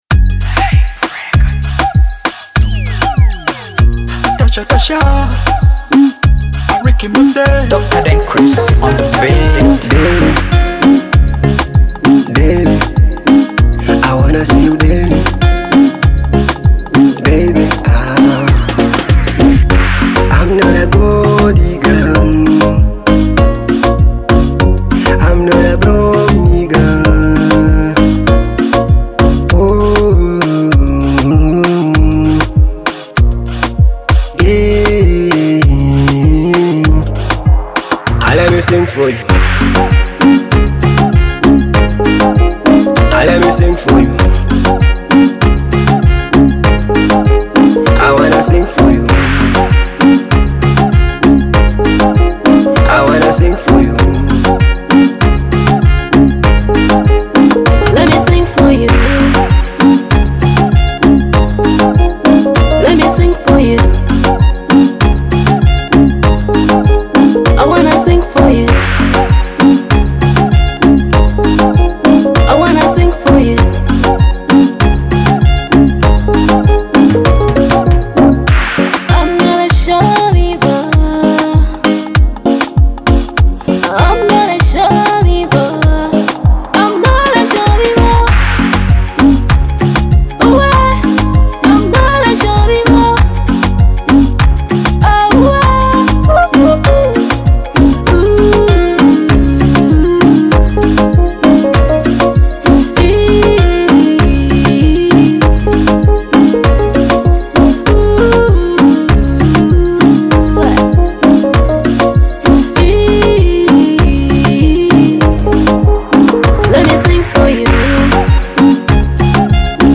electrifying love hit